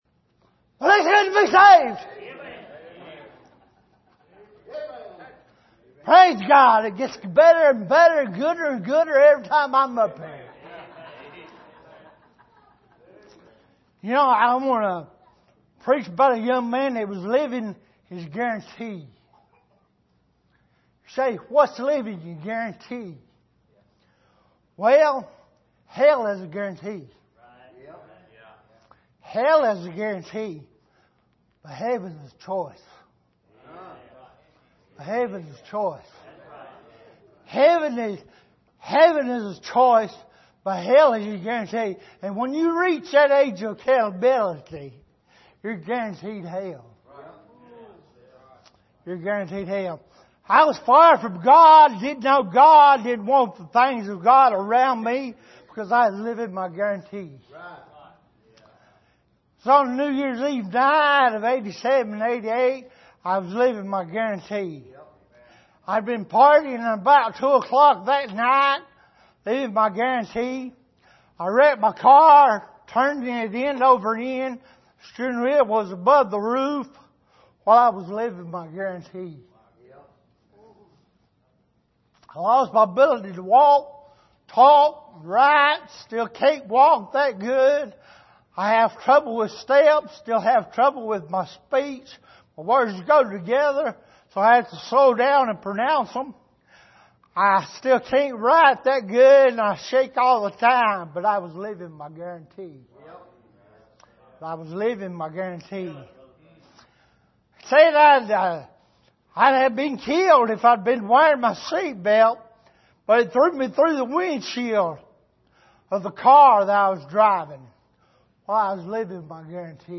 2019 Missions Conference Service: Missions Conference Living Your Guarantee « Jesus Is The True Vine Can God Trust You With Jesus?